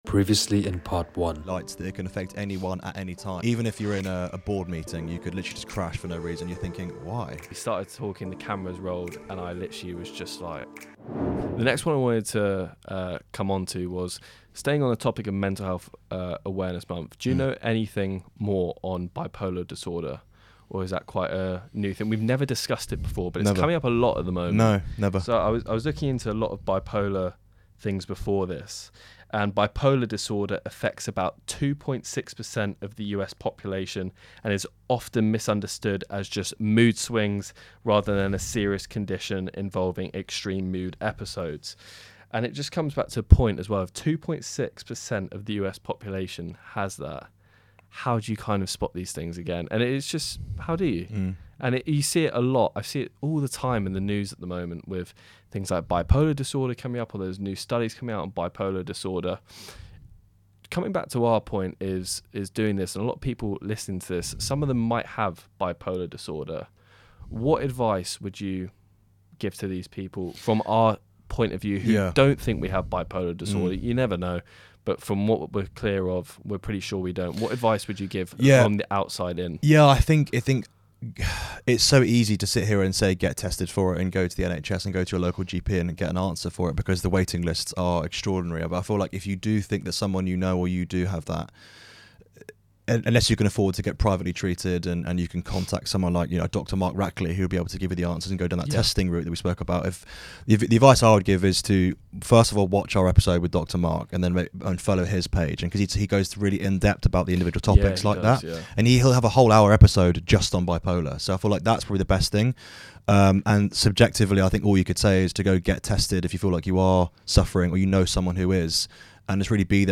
Our hosts discuss personal experiences and expert insights to help you understand and manage bipolar disorder effectively. Discover the benefits of cold water therapy, its impact on mental well-being, and practical tips for incorporating it into your routine.